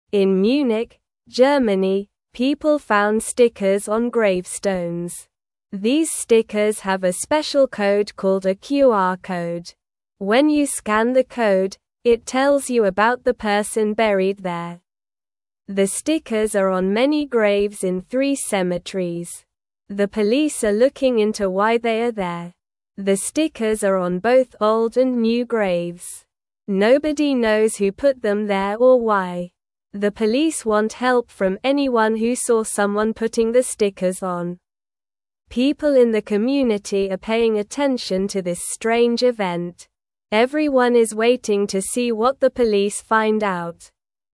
Slow
English-Newsroom-Beginner-SLOW-Reading-Stickers-on-Graves-Tell-Stories-of-the-Past.mp3